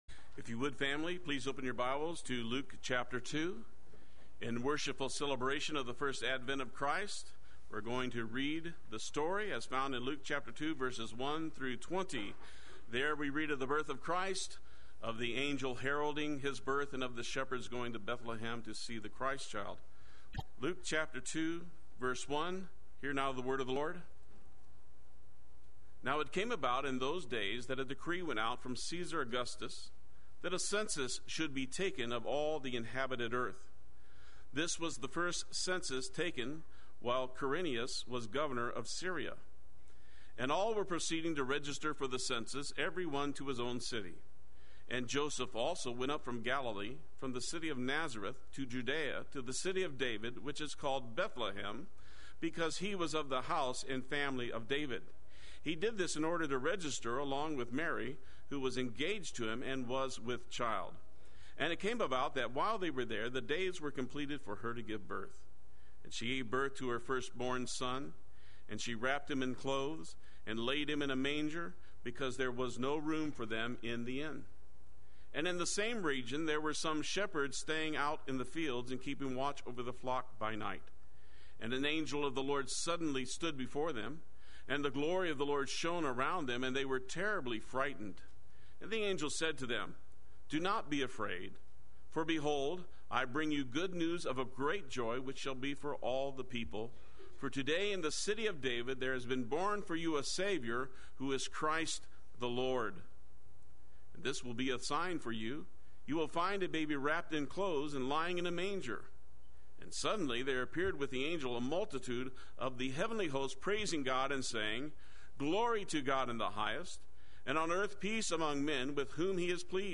Play Sermon Get HCF Teaching Automatically.
God Rest Ye Merry Sunday Worship